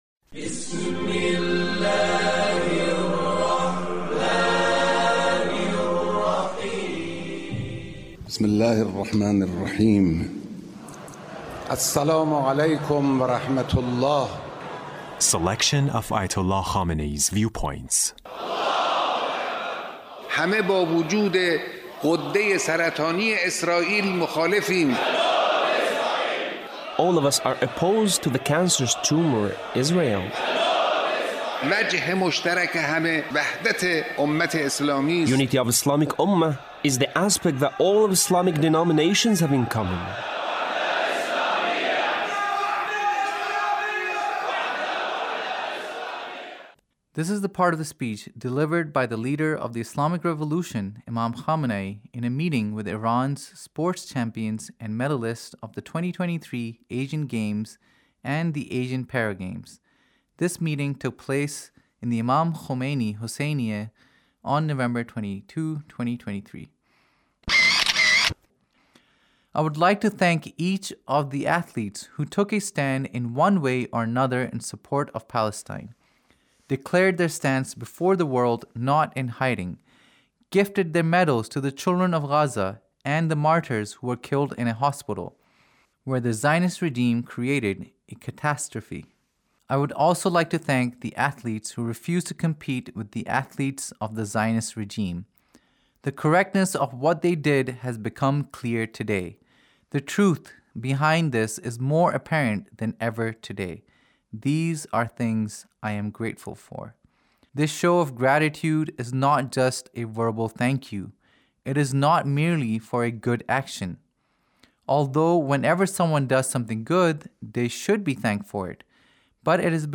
Leader's Speech (1888)
Leader's Speech in a meeting with Iran’s sports champions and medalists